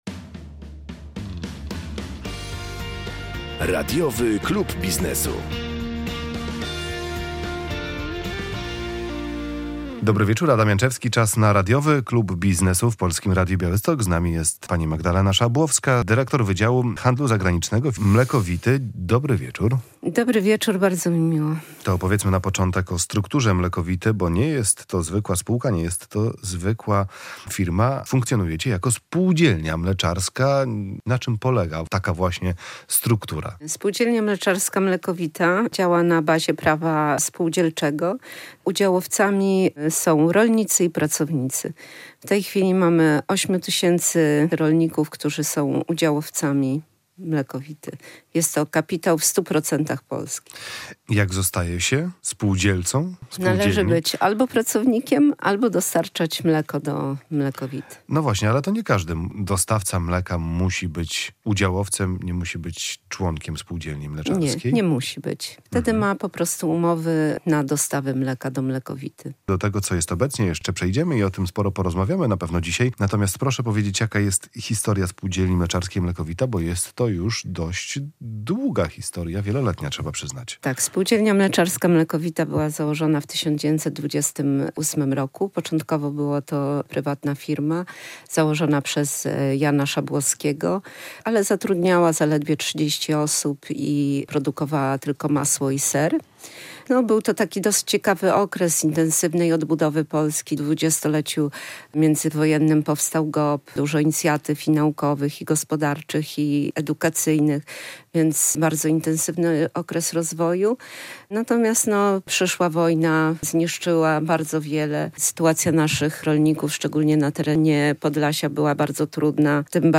W kolejnej audycji z cyklu Radiowy Klub Biznesu w Polskim Radiu Białystok mówimy o Mlekovicie.